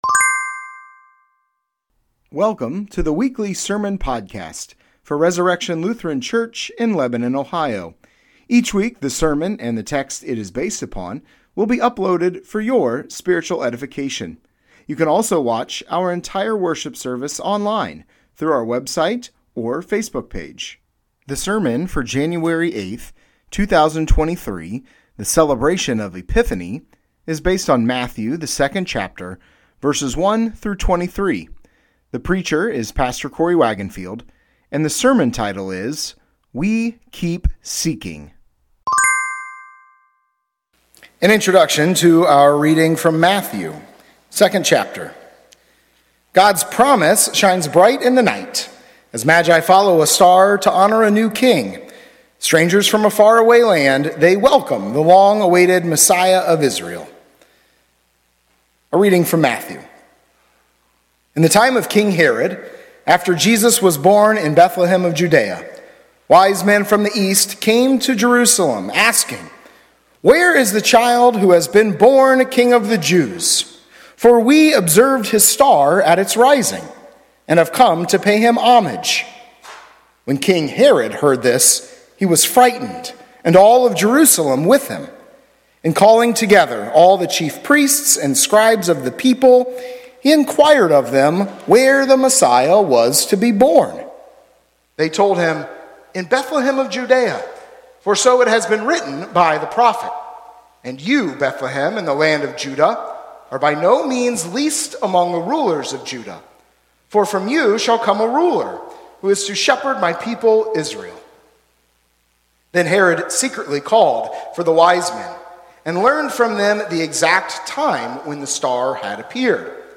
Sermons | Resurrection Lutheran Church